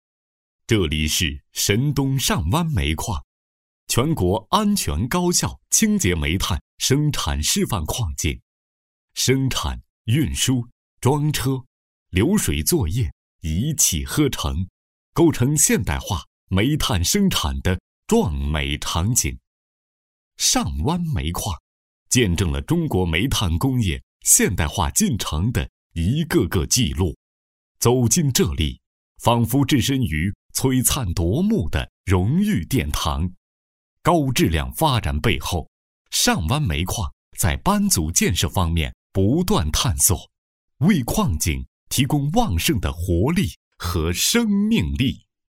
稳重磁性 企业专题,人物专题,医疗专题,学校专题,产品解说,警示教育,规划总结配音
大气稳重男中音，声音偏年轻。可模仿丁文山、赵忠祥、舌尖中国。